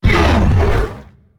attack1.ogg